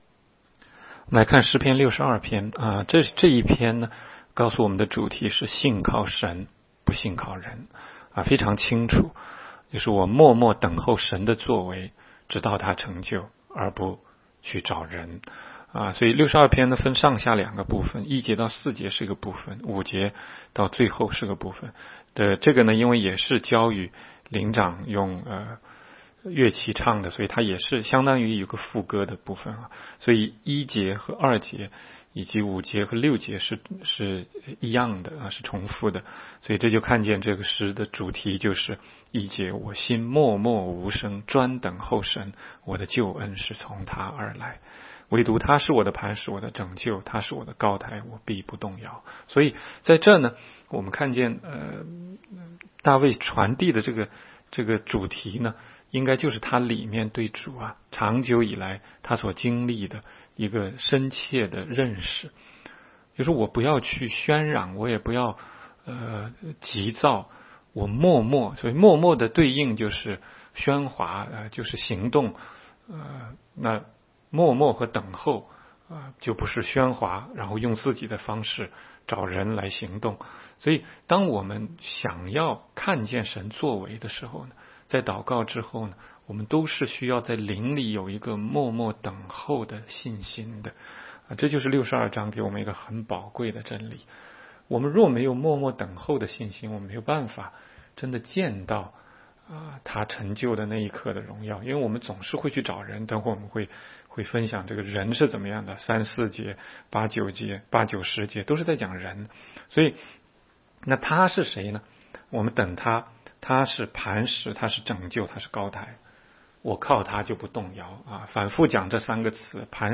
16街讲道录音 - 每日读经-《诗篇》62章